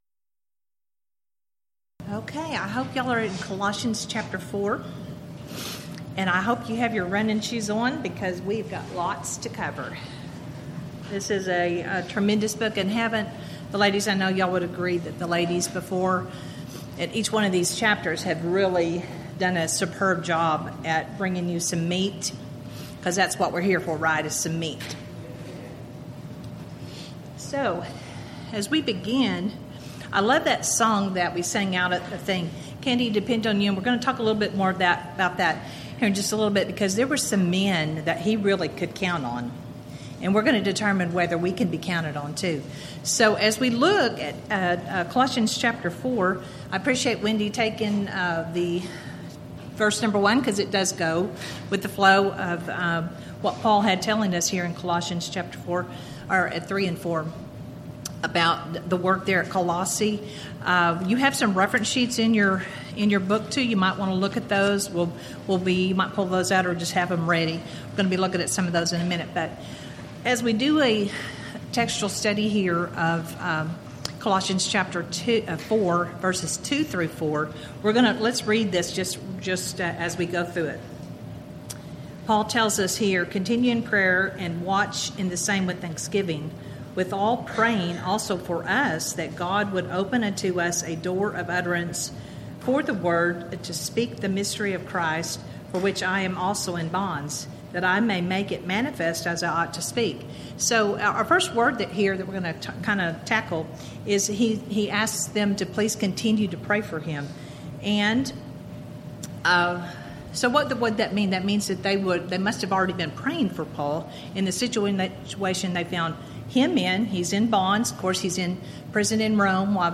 Event: 2014 Texas Ladies in Christ Retreat
Ladies Sessions
lecture